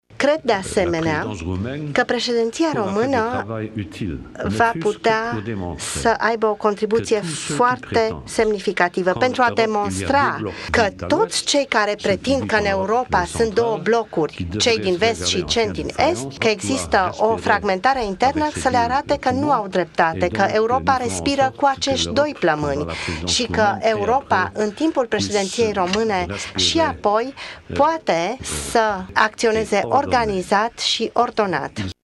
11-ian-declaratie-Juncker.mp3